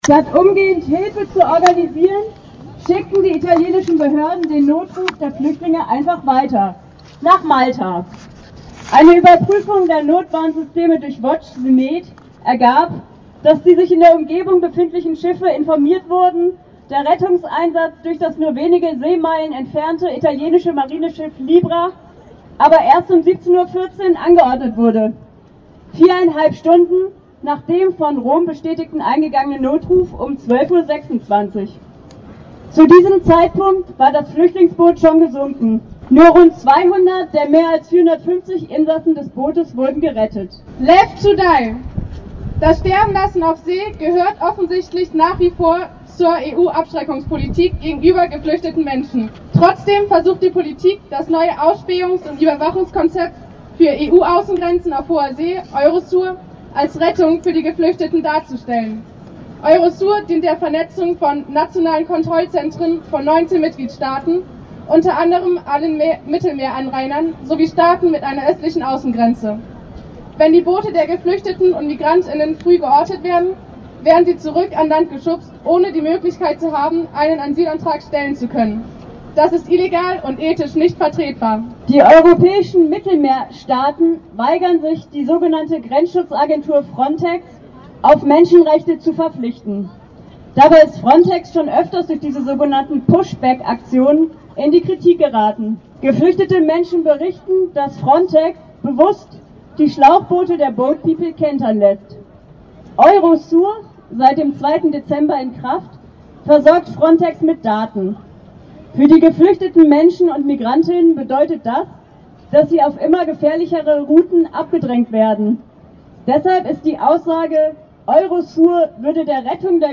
Demonstration „Freiheit stirbt mit Sicherheit“ & Straßentheater in Freiburg